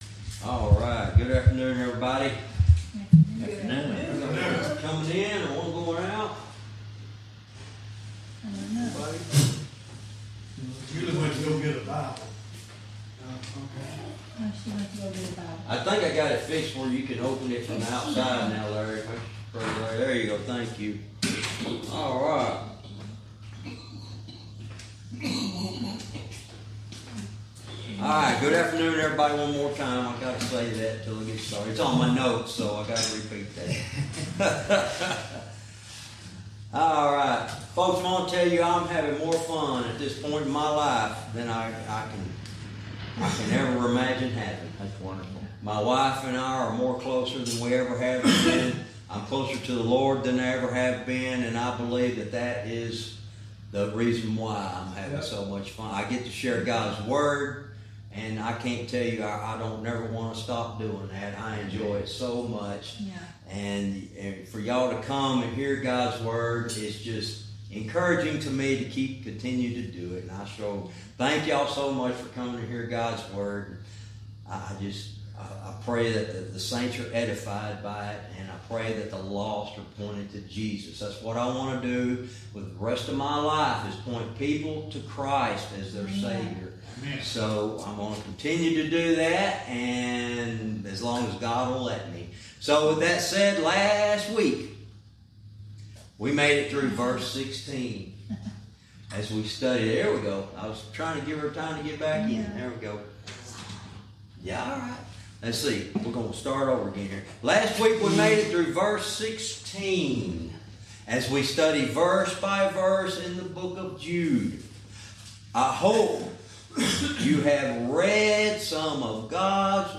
Verse by verse teaching - Jude lesson 73 verse 17